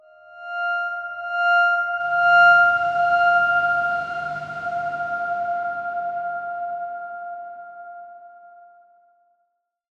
X_Darkswarm-F5-mf.wav